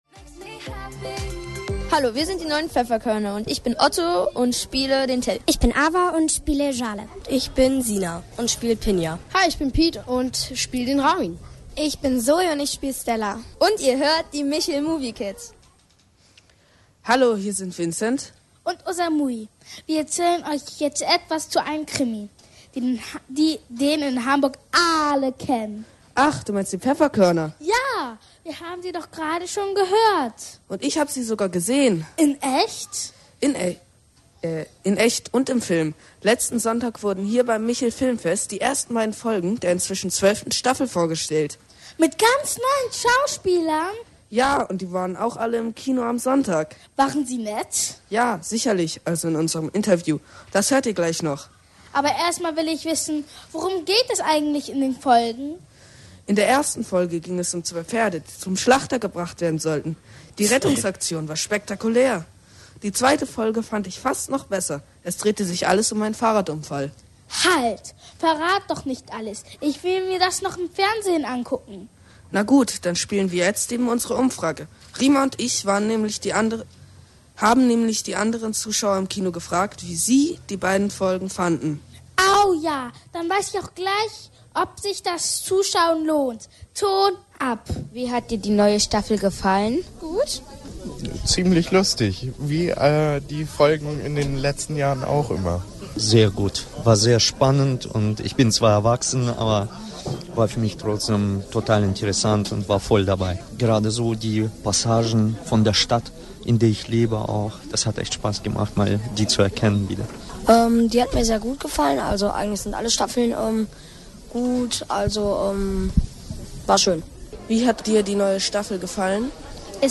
Viele Radiofüchse waren im Reporter-Team der MICHEL MOVIE KIDS und haben am Samstag, 10. Oktober zwei Stunden lang live vom MICHEL Kinder und Jugendfilmfest berichtet.
In der Sendung erzählen wir euch, wie wir die Filme fanden und ihr hört tolle Interviews mit Regisseuren und Schauspielern.